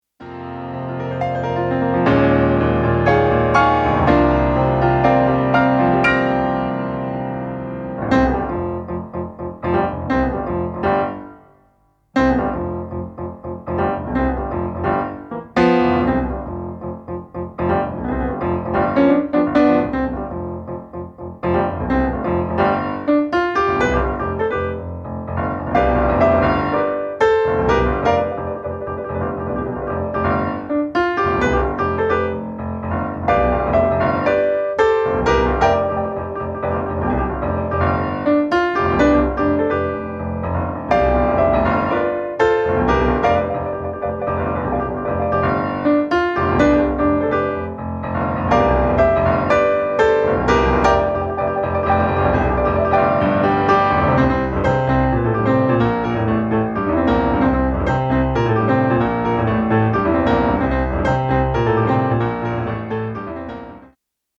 華麗に奏でられるロックの名曲の数々に思わずニヤリです。